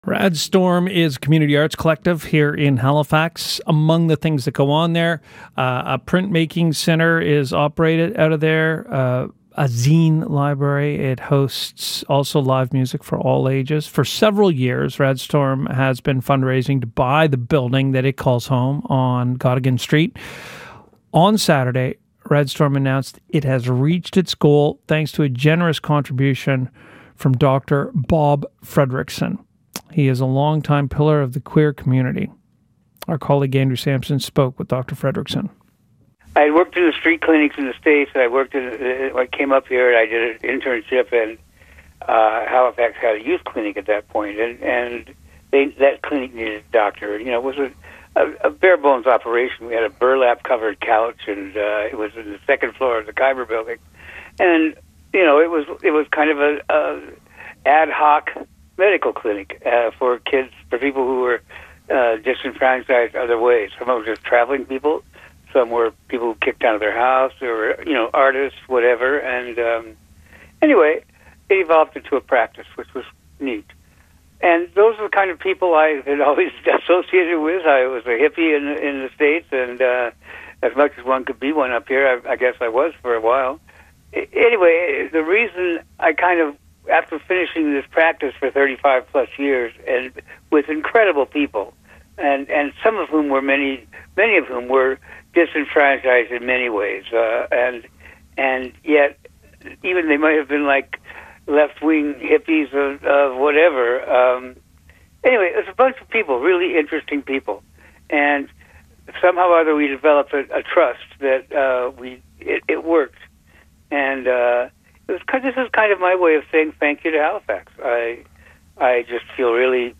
News coverage: CBC Radio (4:11 mp3) a few days before; Global TV (2:29 mp4) coverage of the party on May 25, 2025 ; CTV News (2-page pdf).